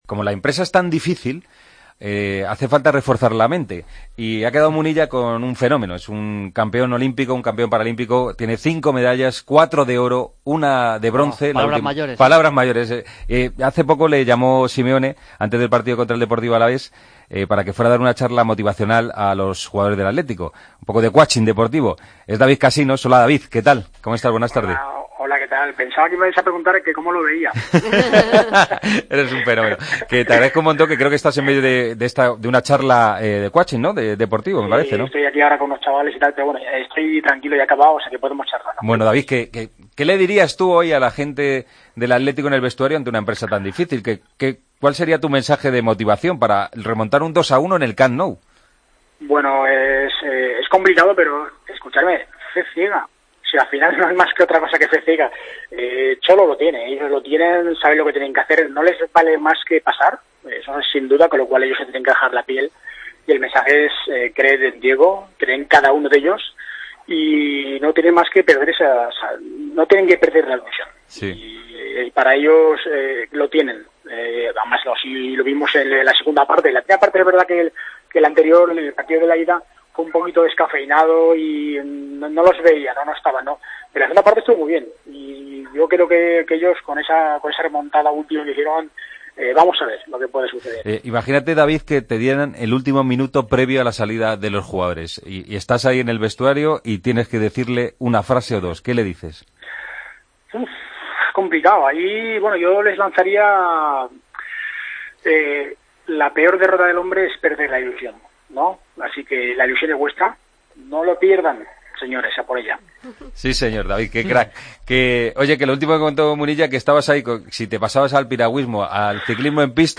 Hablamos con el paralímpico español: "El Atlético tiene que tener fe ciega.